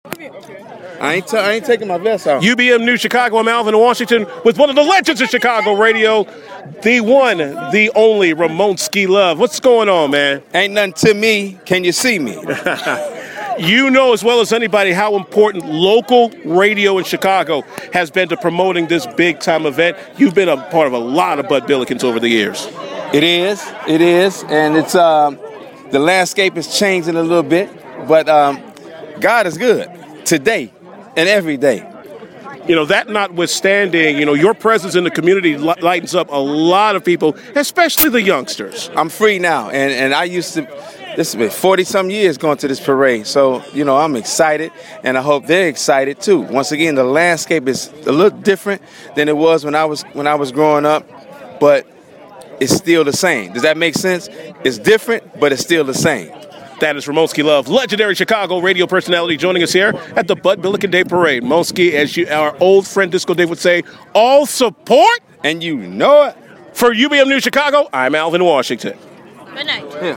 at the Bud Billiken parade